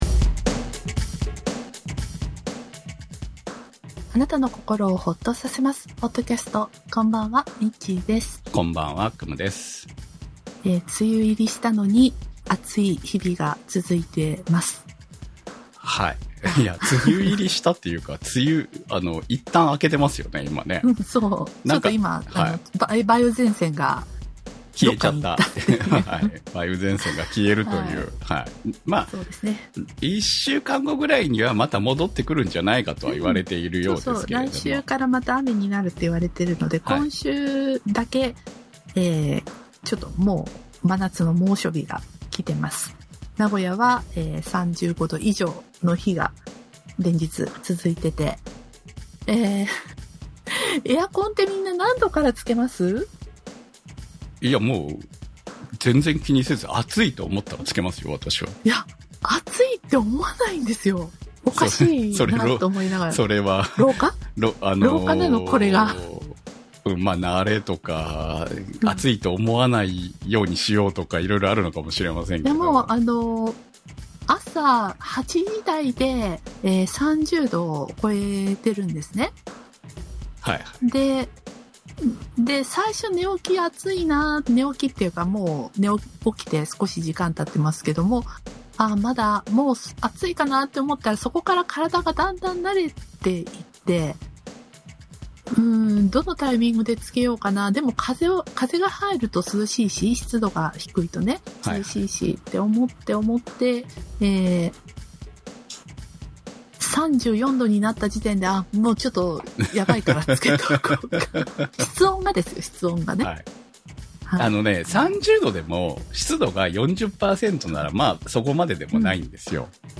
お聞き苦しい状況です。